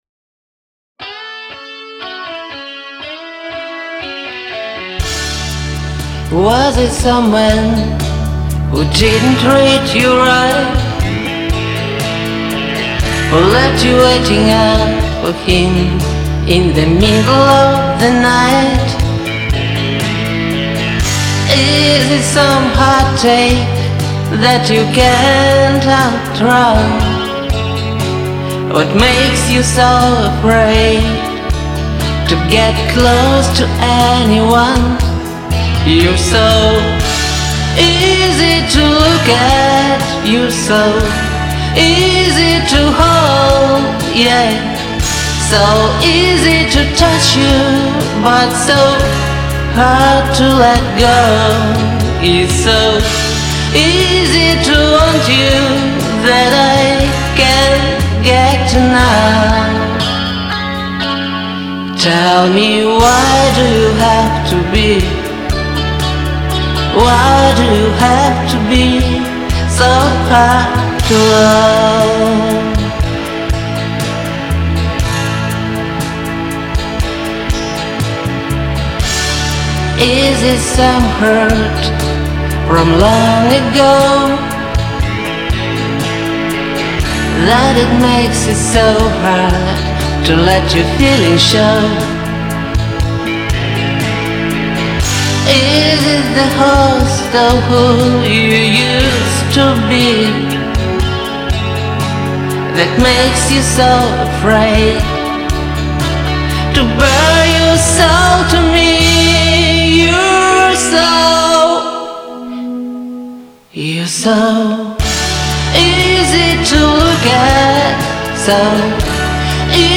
Звук правда неплохой, поэтому и не вытерла ее еще.